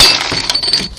glass.mp3